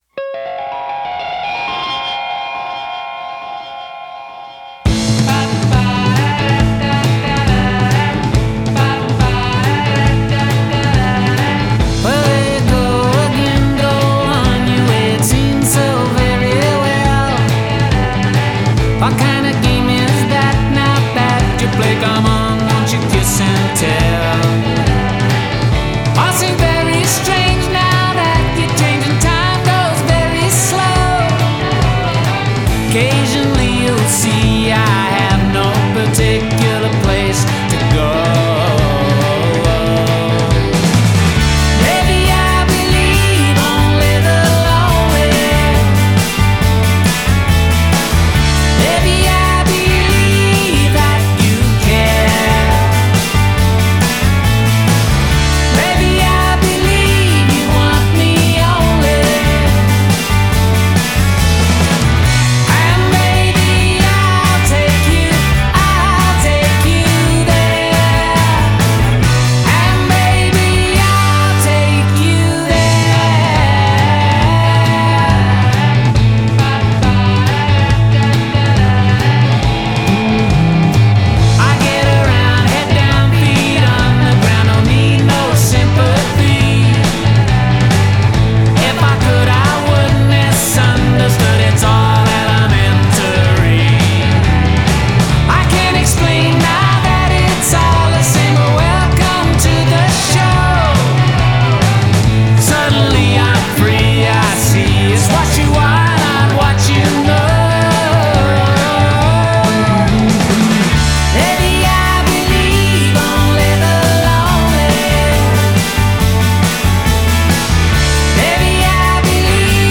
California’s 1960s sunshine pop